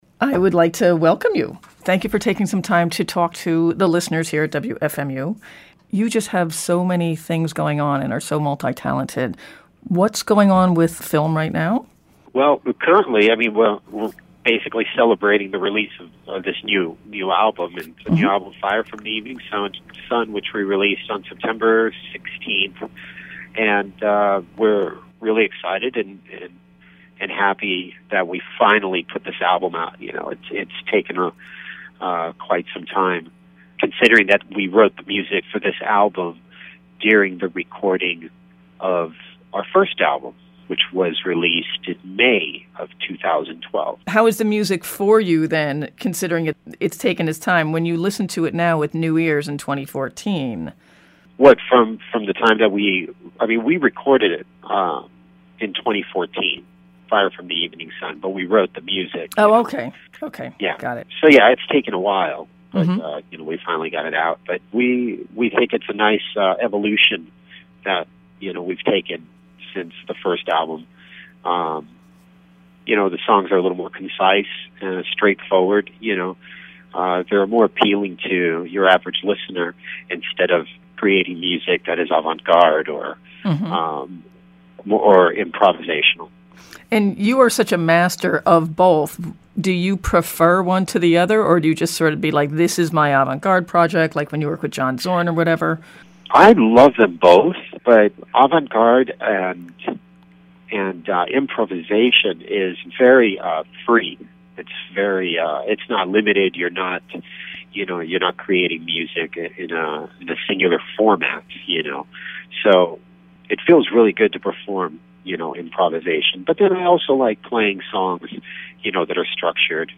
Podcast #41: Dave Lombardo interview from Jan 6, 2015